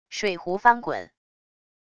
水湖翻滚wav音频